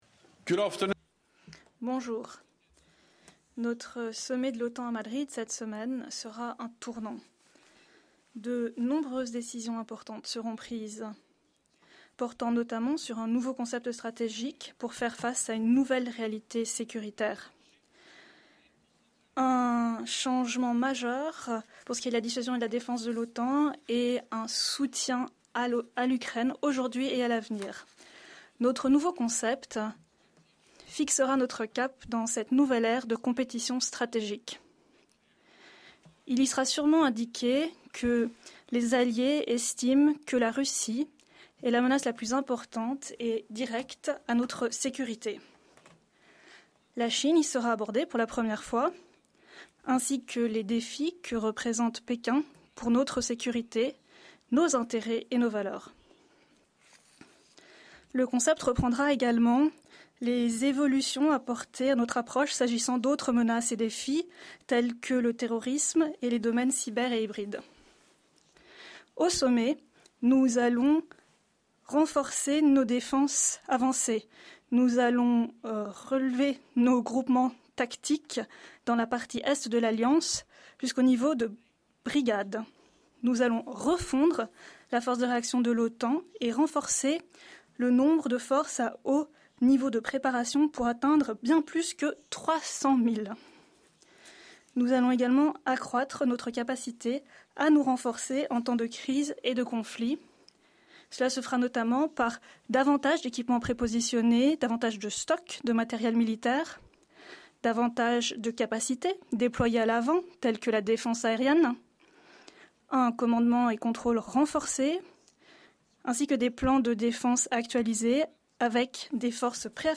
Pre-Summit press conference
by NATO Secretary General Jens Stoltenberg